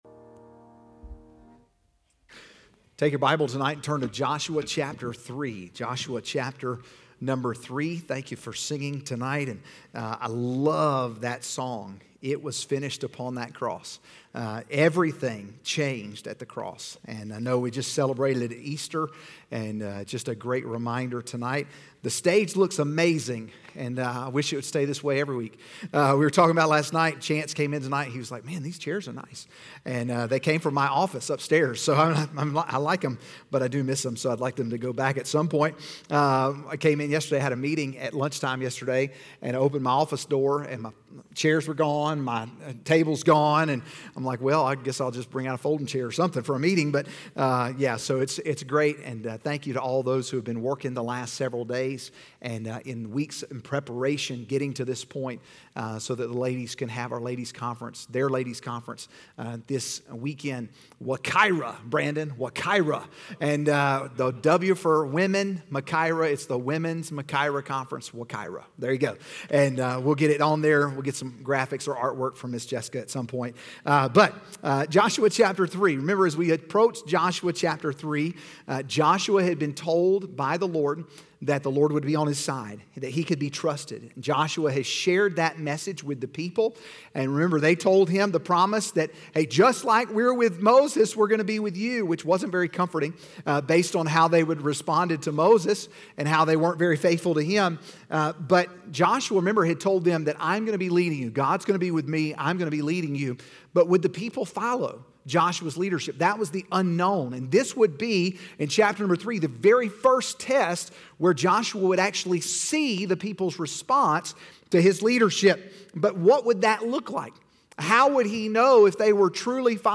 Sermons from Crossroads Baptist Church: Fishersville, VA